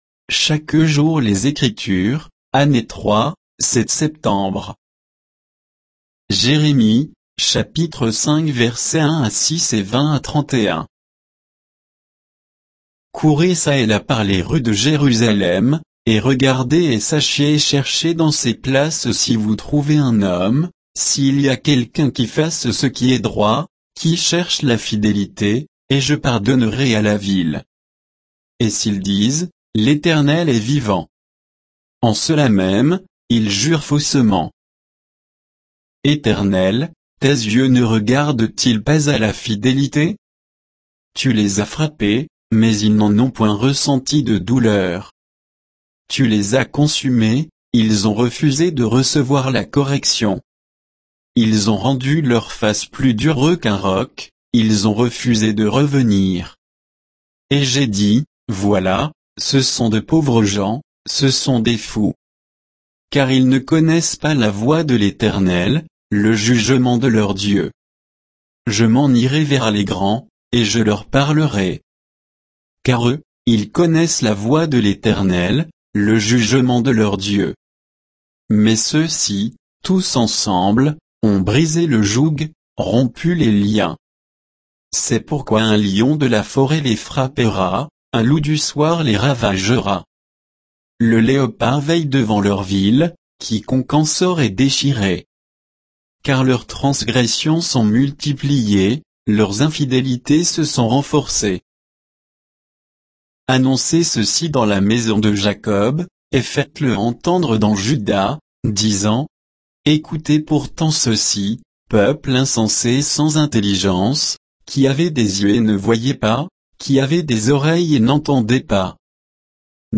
Méditation quoditienne de Chaque jour les Écritures sur Jérémie 5, 1 à 6, 20 à 31